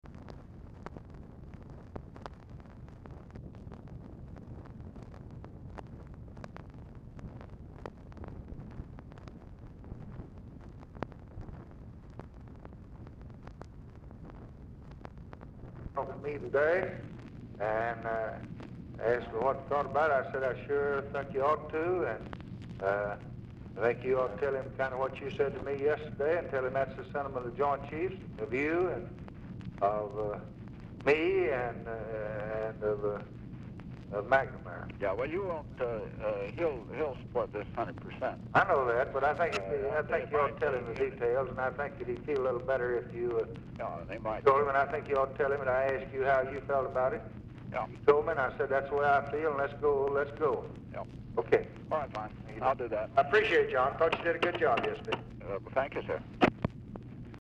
Telephone conversation # 4747, sound recording, LBJ and JOHN MCCONE, 8/5/1964, 3:47PM
RECORDING STARTS AFTER CONVERSATION HAS BEGUN
Format Dictation belt